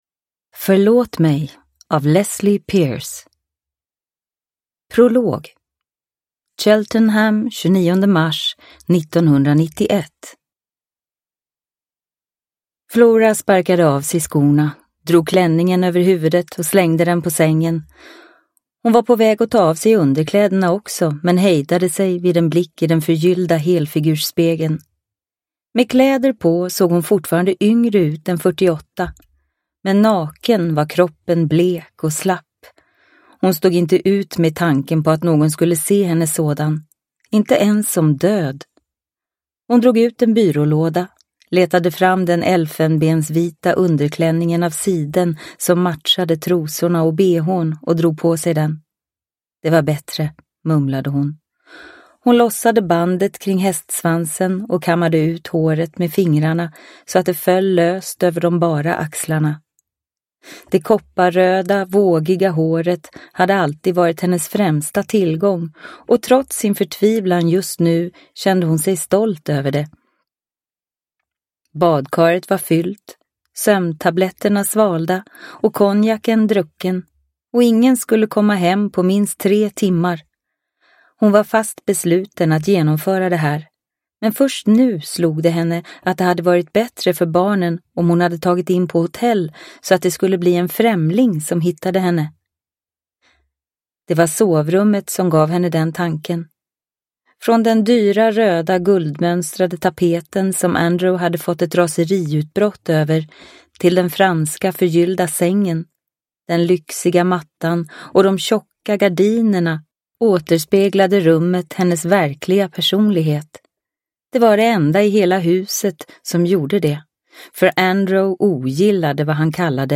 Förlåt mig – Ljudbok – Laddas ner